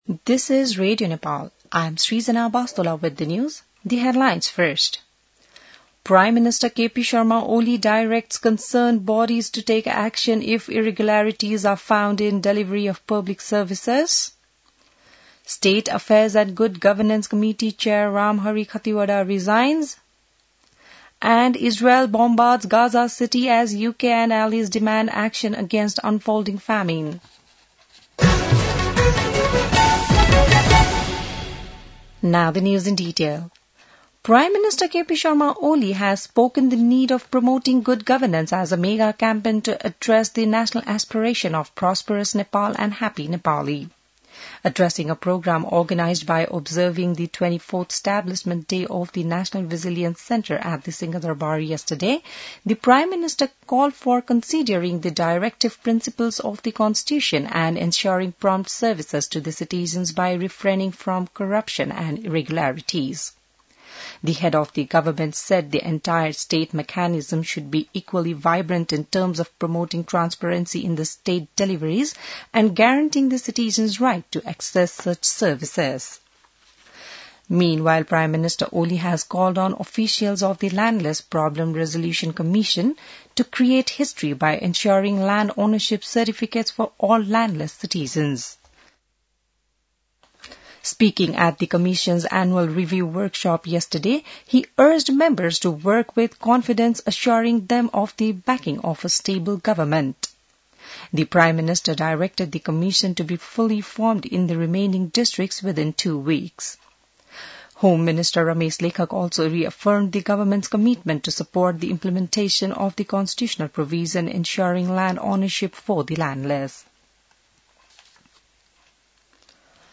बिहान ८ बजेको अङ्ग्रेजी समाचार : २८ साउन , २०८२